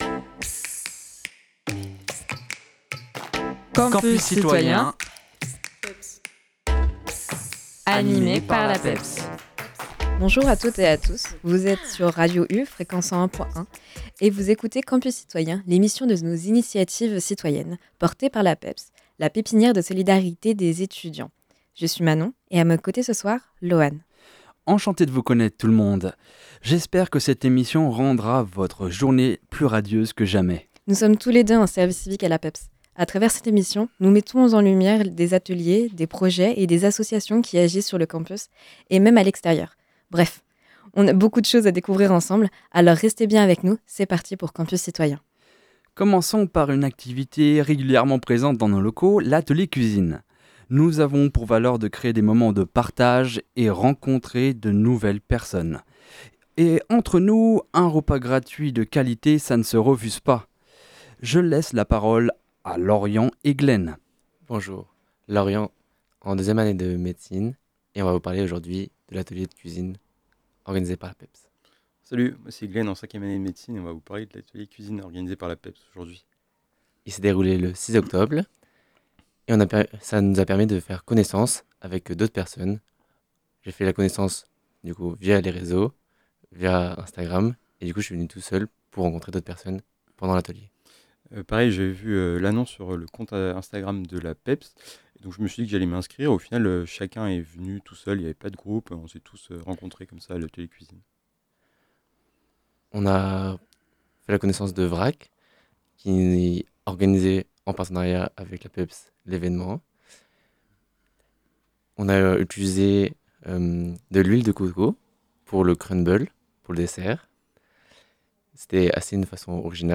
Le tout accompagné de chroniques littéraires.